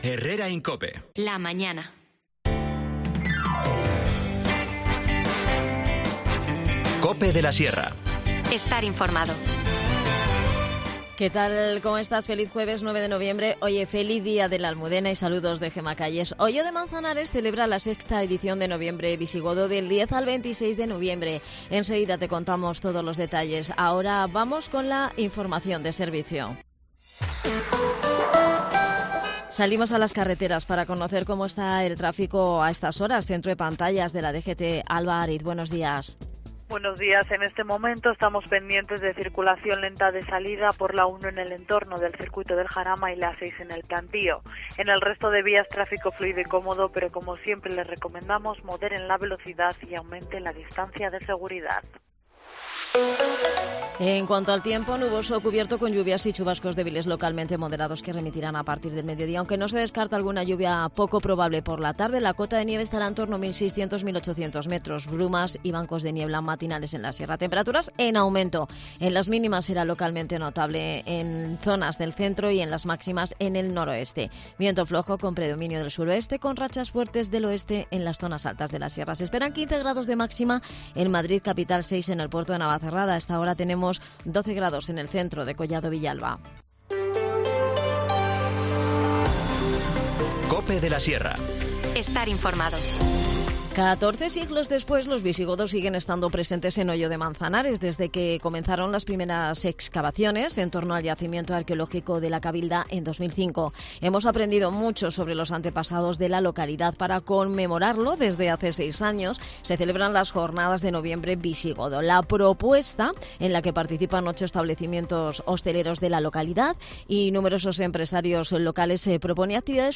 Y concluimos la entrevista adelantando los detalles de la campaña escolar 2023-2024 en el Teatro de la Casa de Cultura.